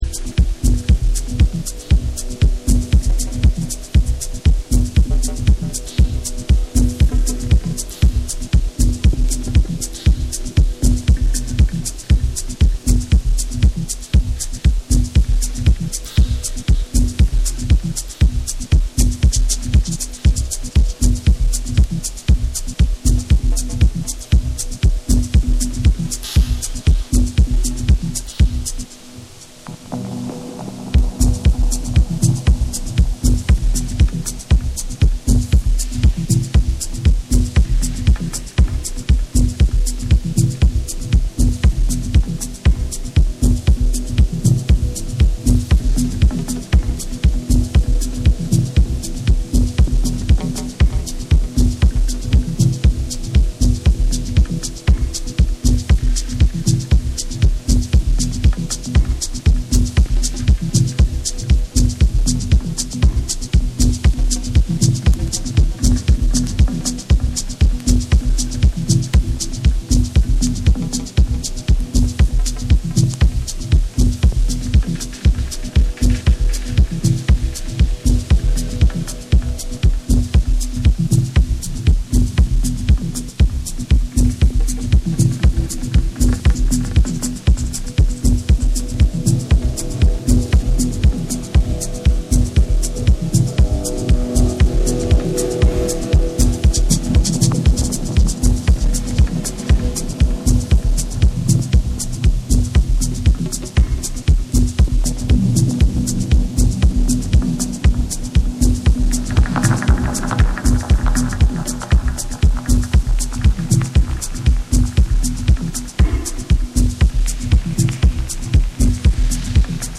重厚感のある4/4トラックを軸にジワジワとサイケデリック空間へと引き込むダブ・テクノを収録！